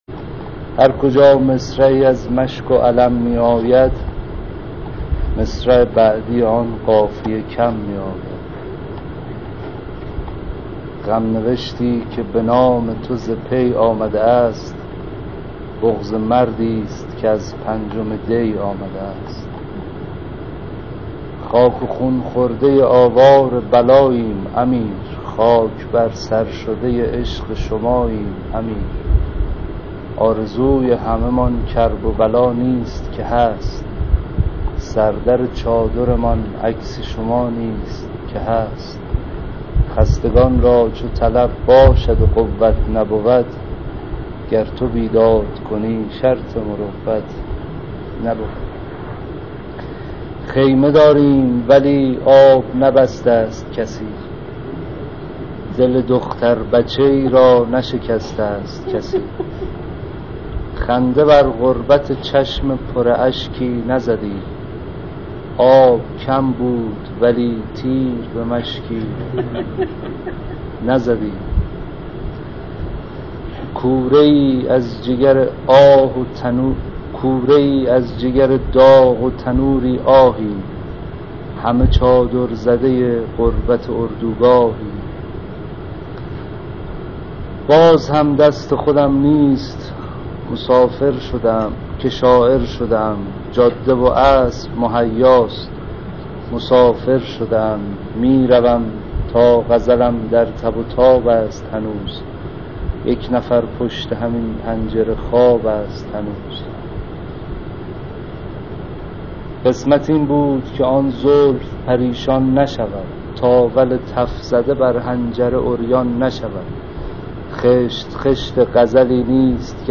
در ادامه متن اشعار و صوت شعر خوانی شعرا را مشاهده می کنید: